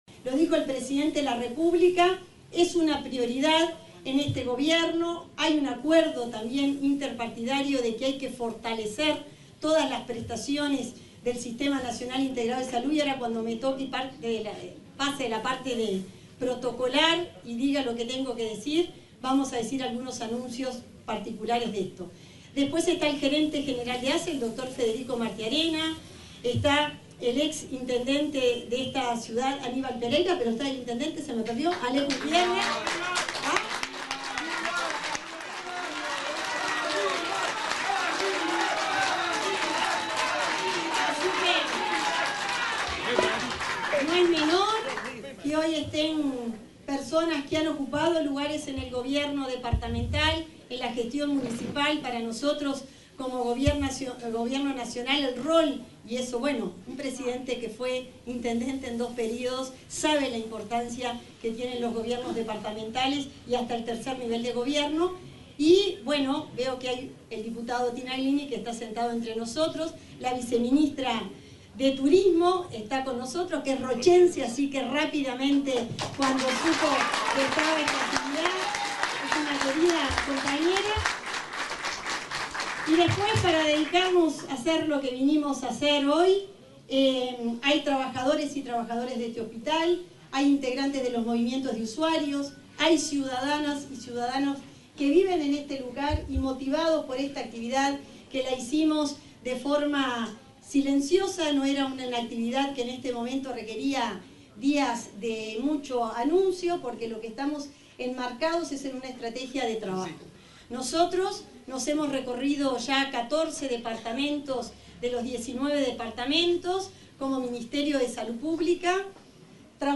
Palabras de la ministra de Salud Pública, Cristina Lustemberg
Palabras de la ministra de Salud Pública, Cristina Lustemberg 02/05/2025 Compartir Facebook X Copiar enlace WhatsApp LinkedIn La ministra de Salud Pública, Cristina Lustemberg, participó, este viernes 2 en el centro auxiliar del Chuy, departamento de Rocha, en el lanzamiento de la Comisión Binacional Asesora de Frontera.